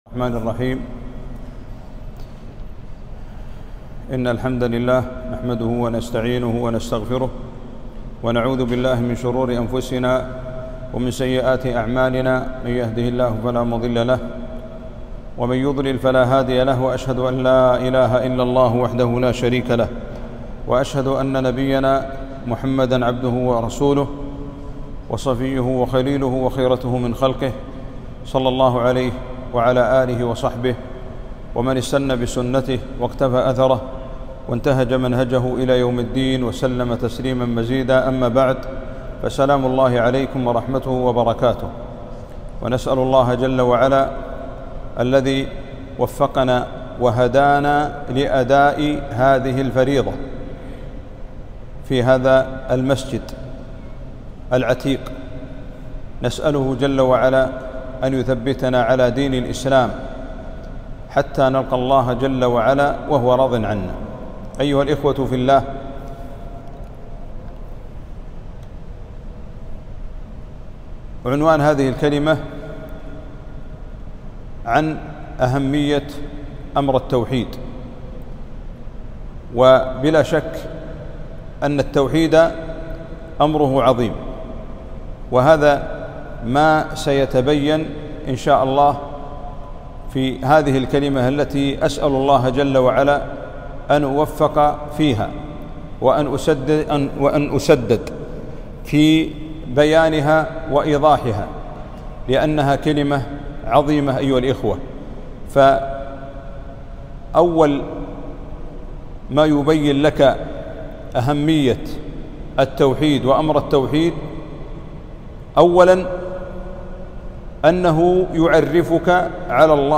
محاضرة - تعظيم أمر التوحيد.